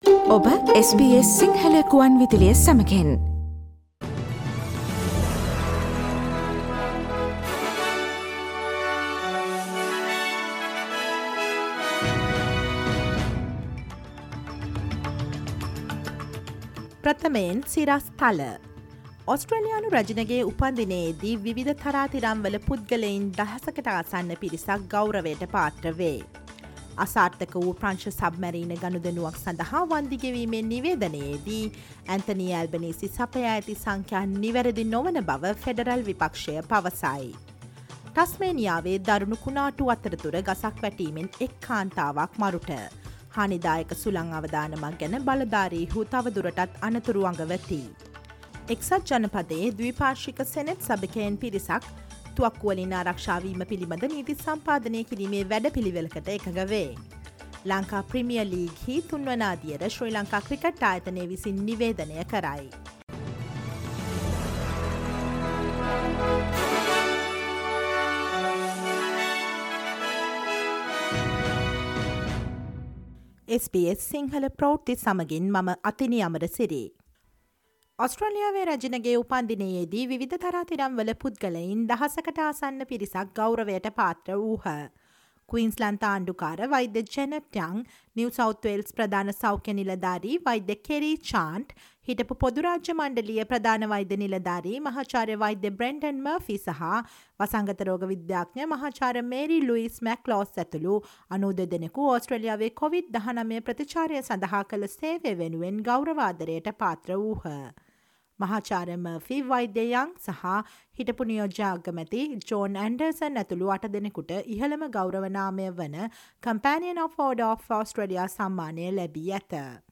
ඔස්ට්‍රේලියාවේ නවතම පුවත් මෙන්ම විදෙස් පුවත් සහ ක්‍රීඩා පුවත් රැගත් SBS සිංහල සේවයේ 2022 ජුනි 13 සඳුදා වැඩසටහනේ ප්‍රවෘත්ති ප්‍රකාශයට සවන් දීමට ඉහත ඡායාරූපය මත ඇති speaker සලකුණ මත click කරන්න.